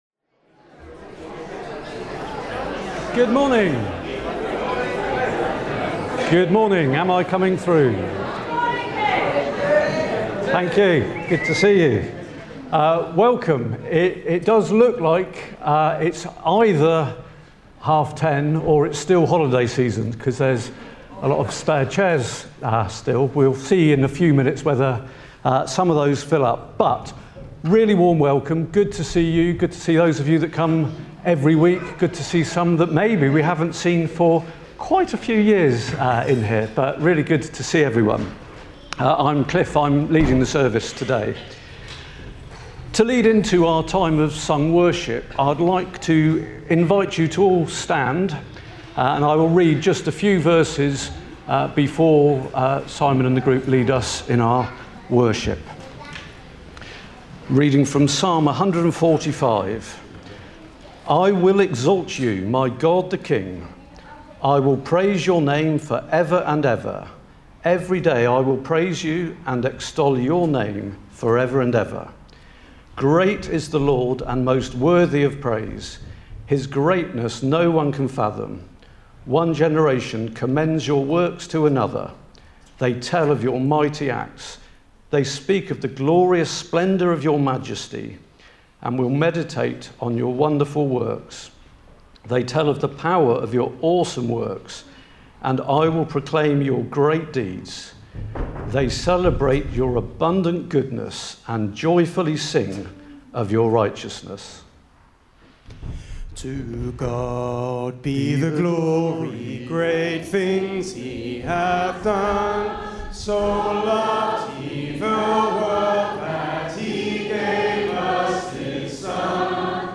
31 August 2025 – Morning Service